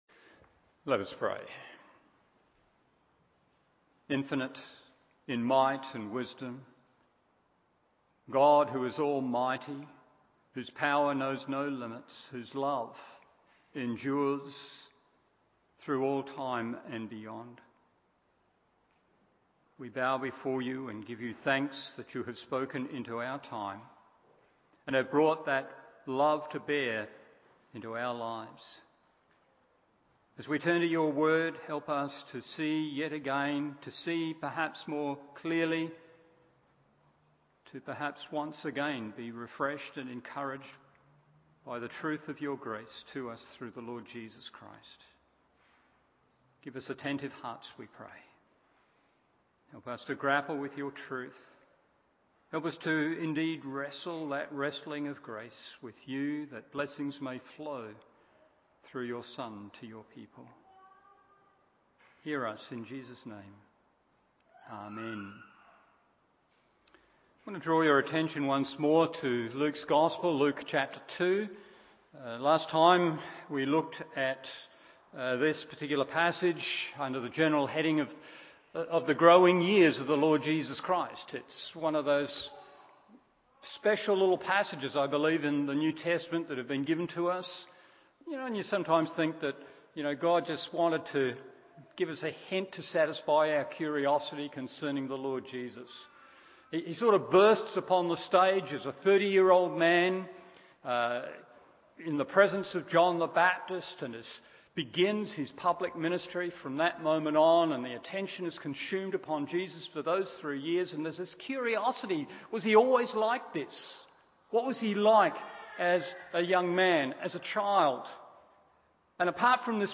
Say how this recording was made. Evening Service Luke 2:39-52 1. Love for God’s Word 2. Delight for God’s Presence 3. Submission to God’s Authority…